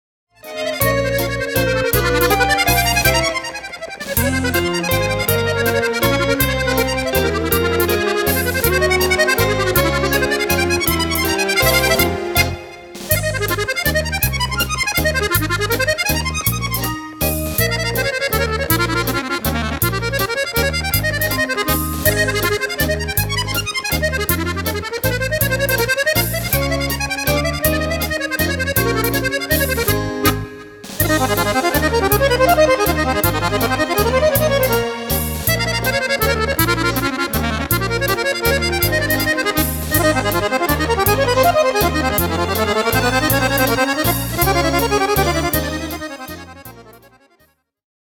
Mazurca
Fisarmonica